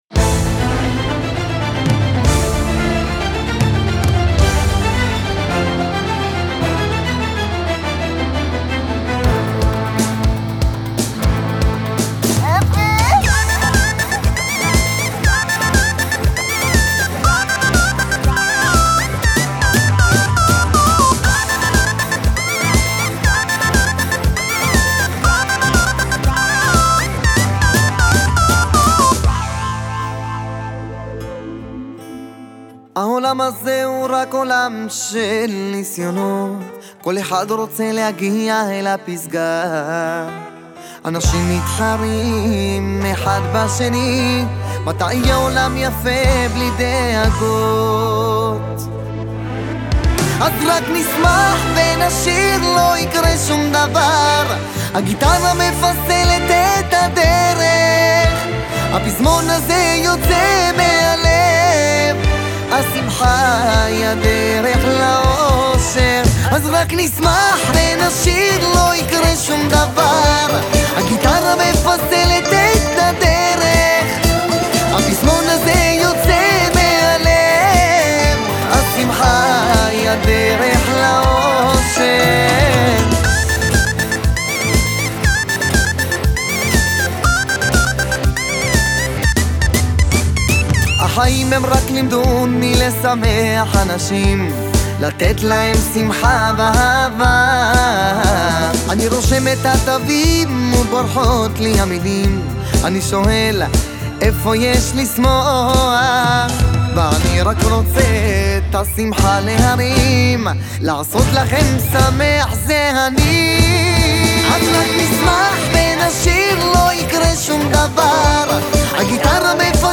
הסינגל המקפיץ